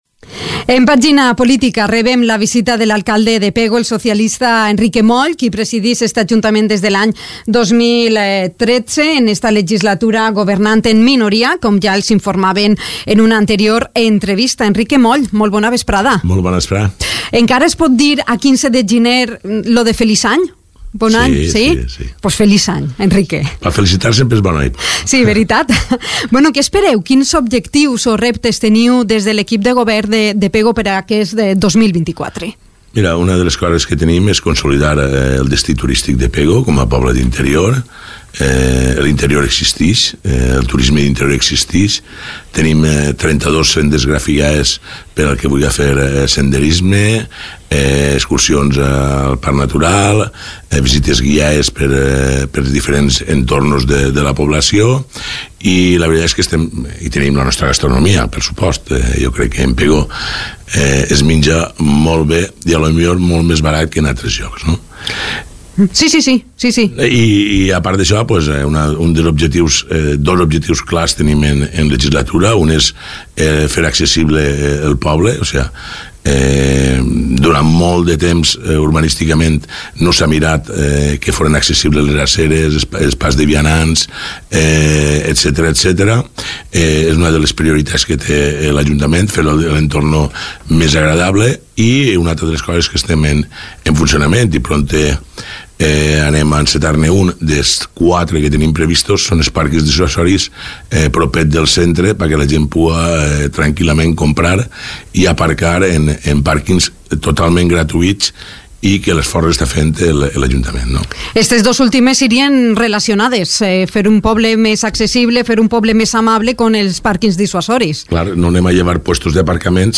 Así lo ha indicado el alcalde pegolino, el socialista Enrique Moll, en su visita al informativo de Dénia FM.
Entrevista-Enrique-Moll.mp3